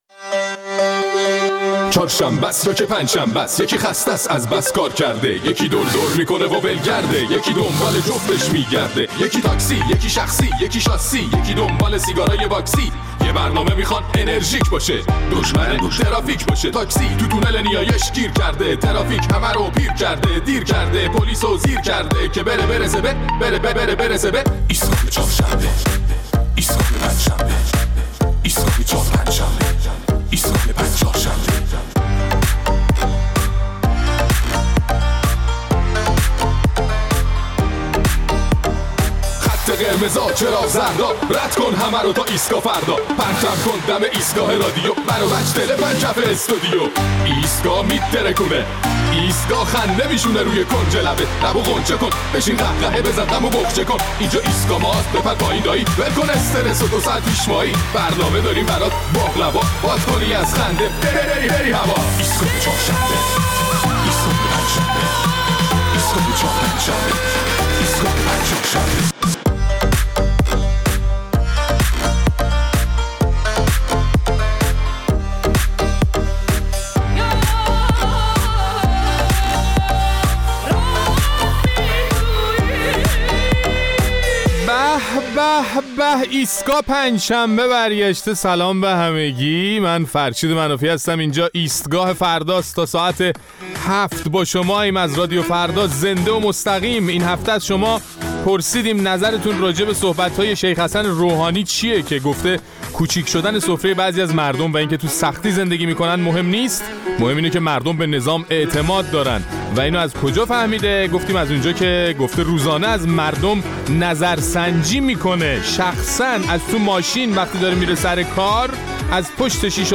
در این برنامه ادامه نظرات شنوندگان ایستگاه فردا را درباره حرف‌های اخیر حسن روحانی در رابطه با نظرسنجی و سفره مردم می‌شنویم.